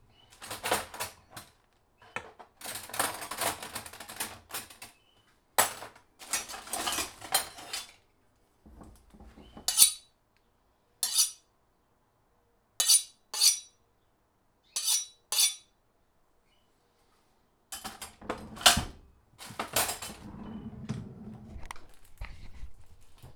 knive drawer sounds - sharpen sounds.wav
Recorded with a Tascam DR 40.
knive_drawer_sounds_-_sharpen_sounds_vyo.wav